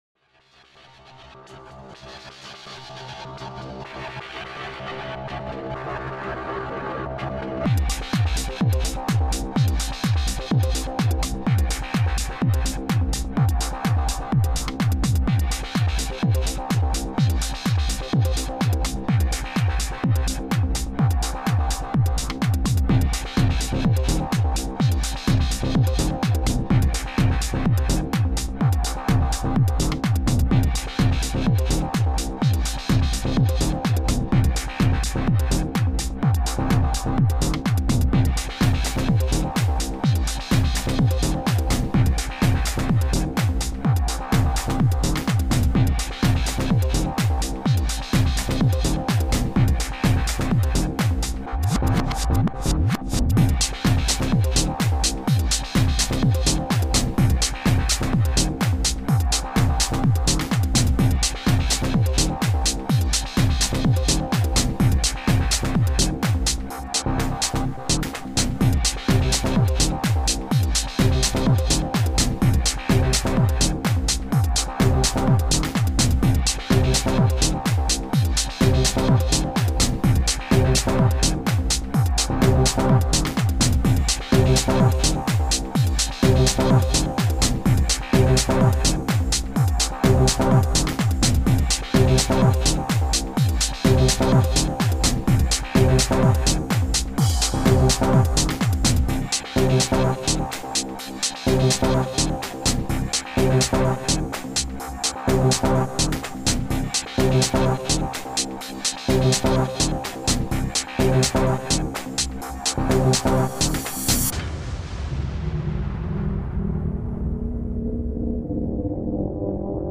Progressive House - 5:35 - BPM 126